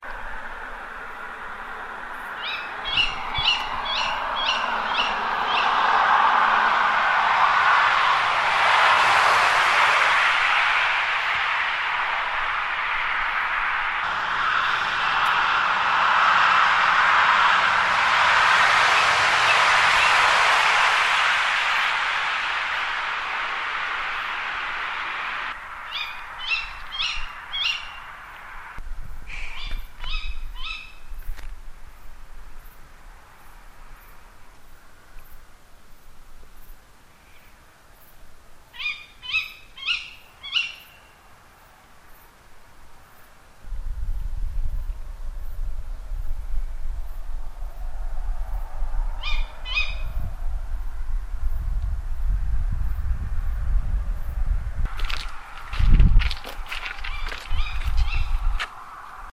Owls recorded a few months ago. sorry about the traffic